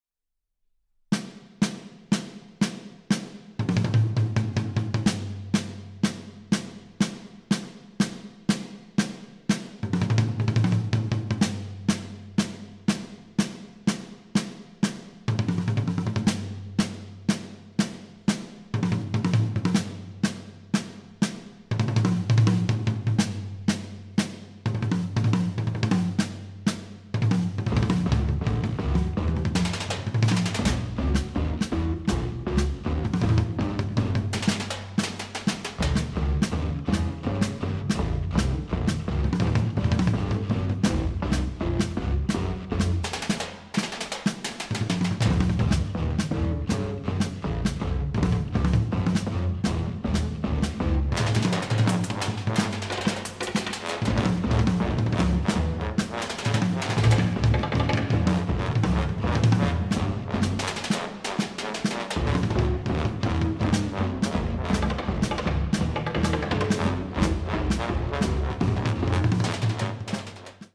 for ensemble